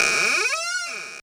Grincement-de-porte.mp3